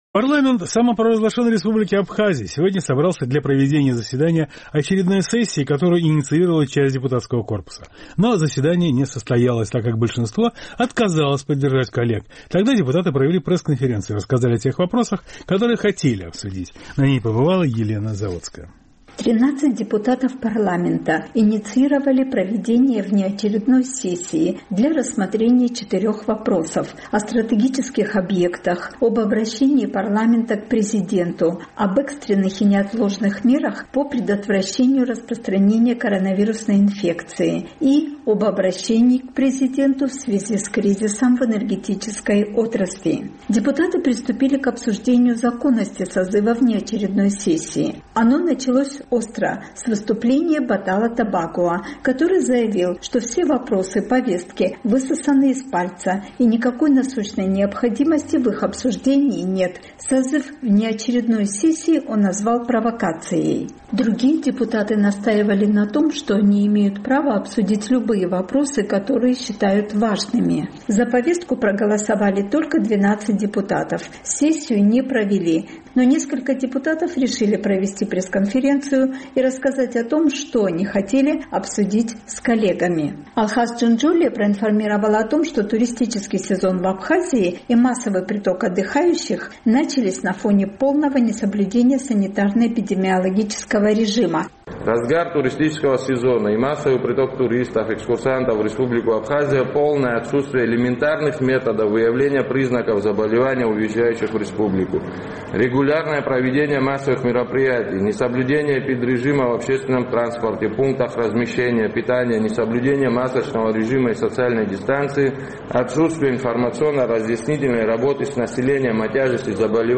Пресс-конференция вместо внеочередной сессии: COVID-19, энергетика, спецобъекты